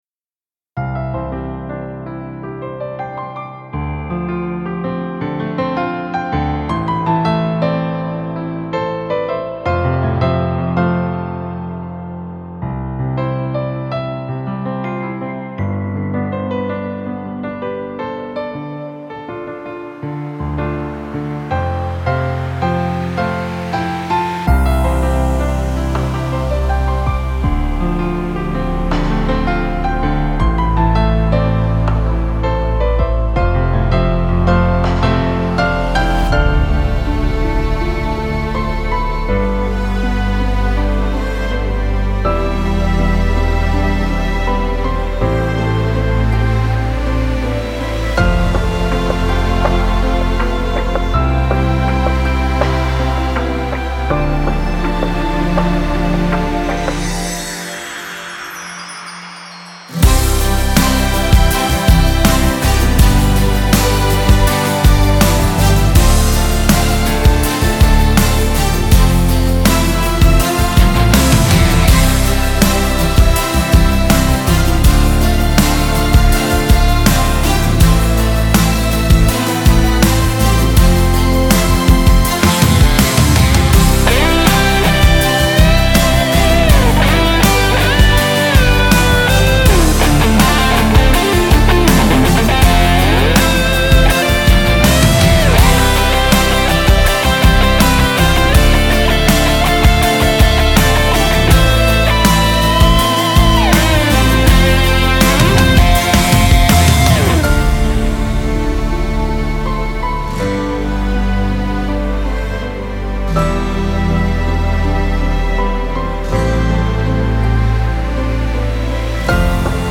Слушать минус
🎶 Детские песни